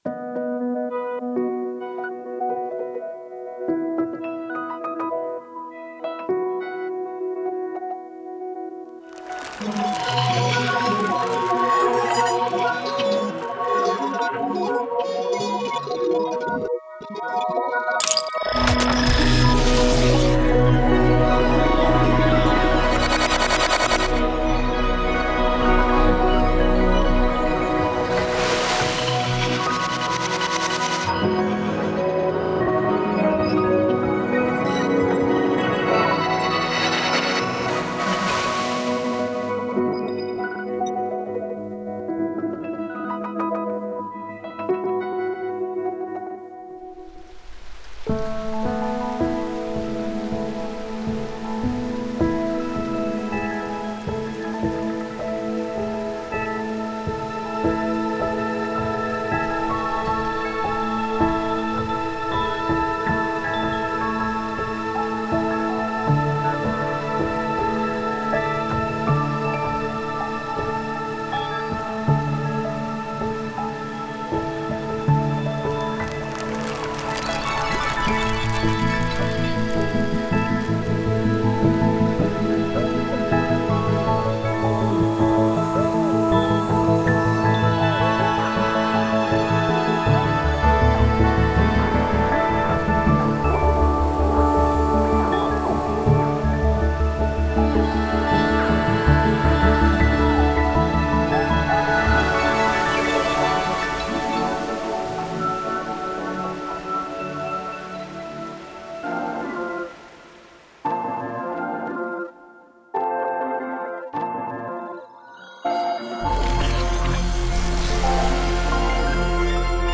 Genre:Ambient
デモサウンドはコチラ↓